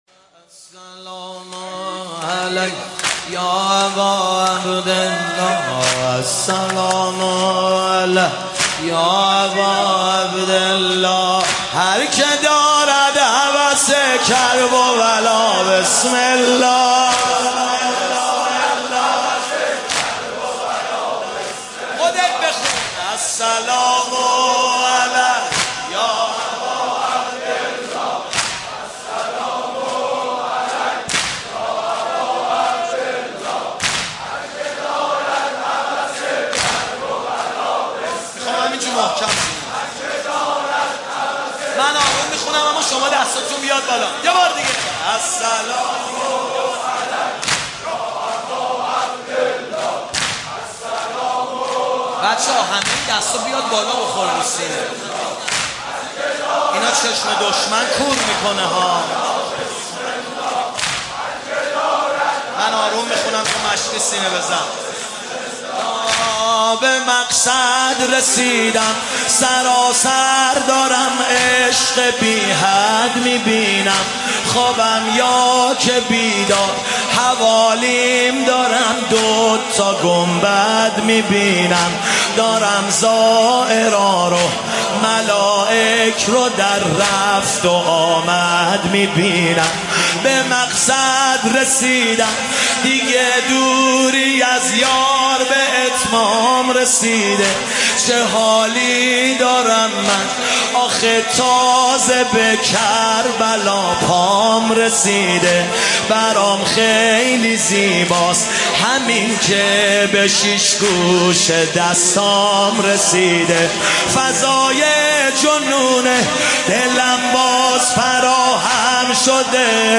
شب پنجم محرم 96/07/3
مداحی اربعین